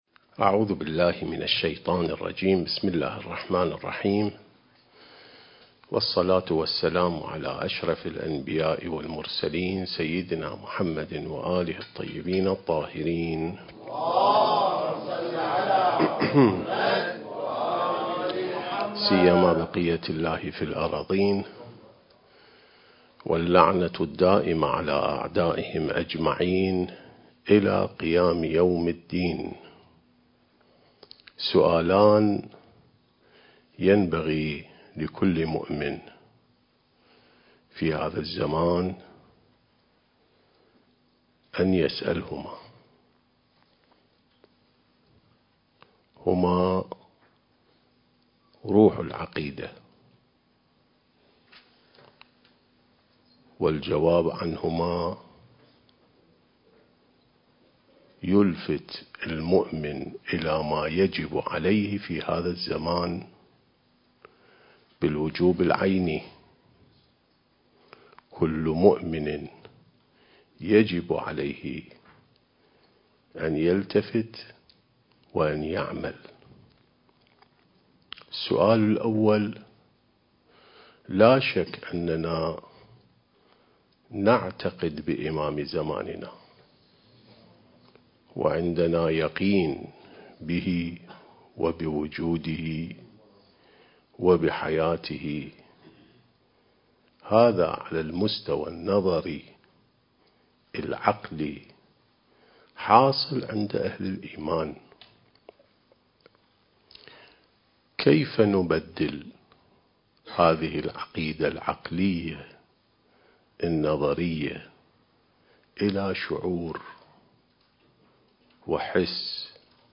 عين السماء ونهج الأنبياء سلسلة محاضرات: الارتباط بالإمام المهدي (عجّل الله فرجه)/ (1)